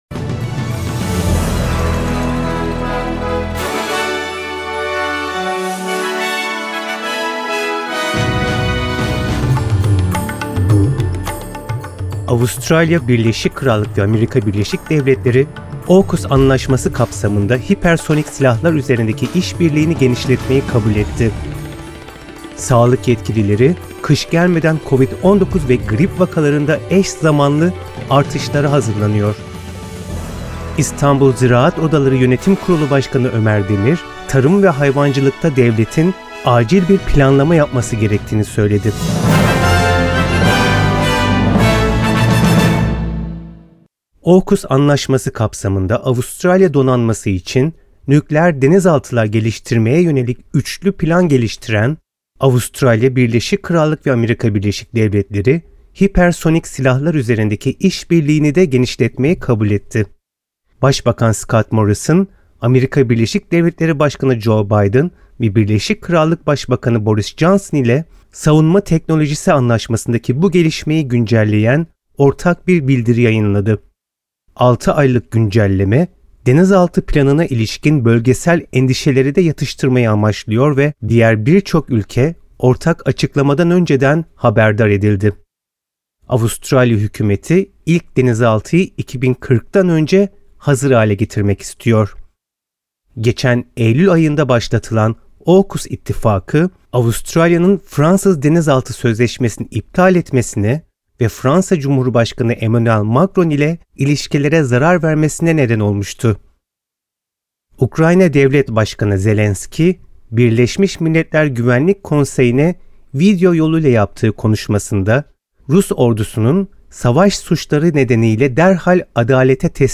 SBS Türkçe Haberler Source: SBS